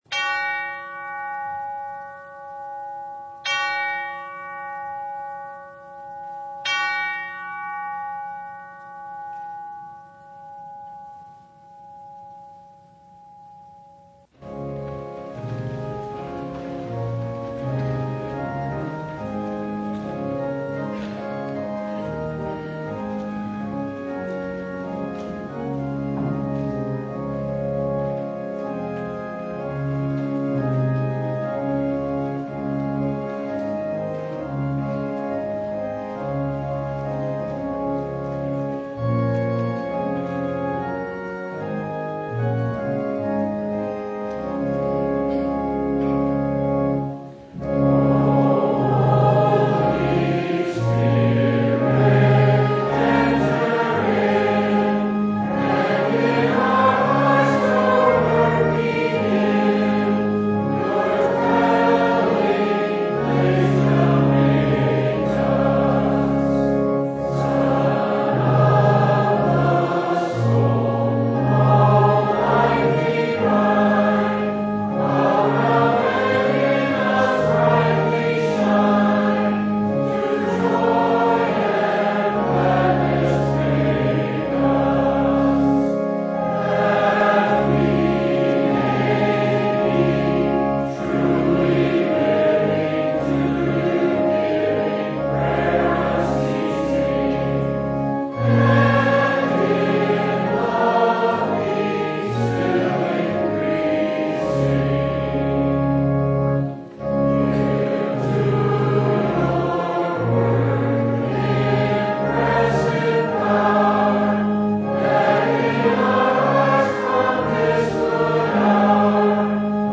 Mark 9:38-50 Service Type: Sunday Jesus speaks words that are hard to hear